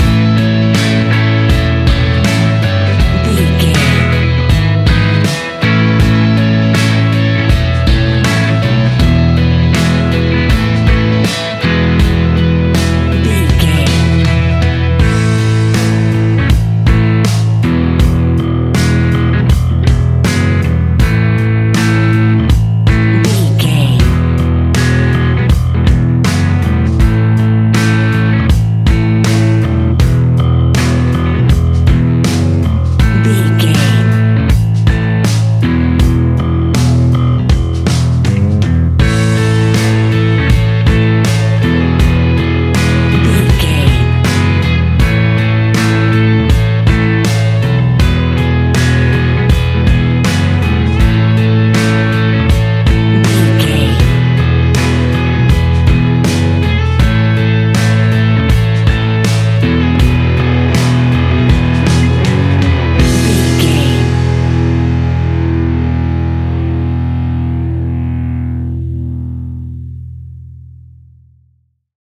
Ionian/Major
indie pop
fun
energetic
uplifting
instrumentals
upbeat
groovy
guitars
bass
drums
piano
organ